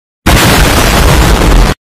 Explosion meme.mp3